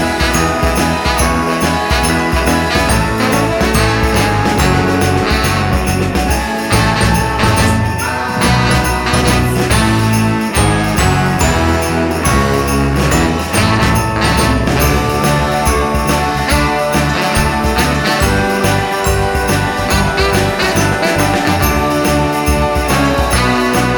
One Semitone Down Christmas 4:10 Buy £1.50